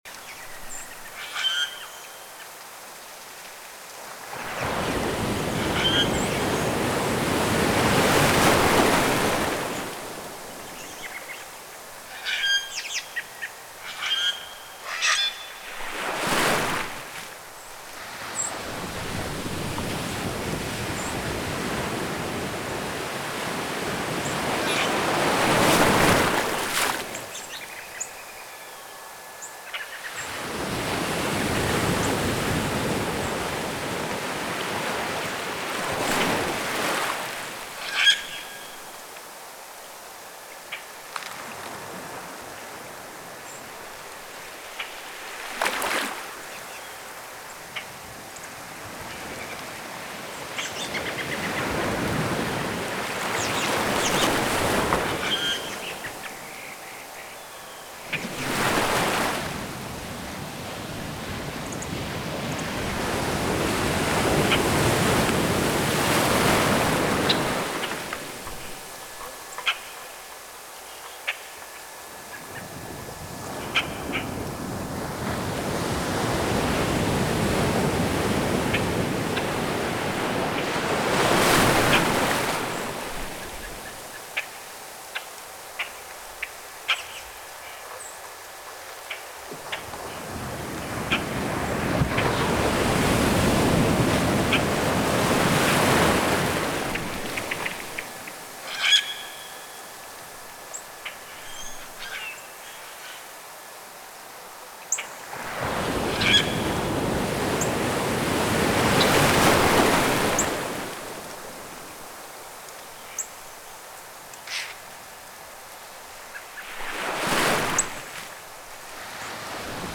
• Audio: Binaural beats, 360° environmental audio, and guided breathing exercises developed in Ableton Live.
• Low frequencies were chosen to blend seamlessly with natural soundscapes.
Natural 360° Soundscape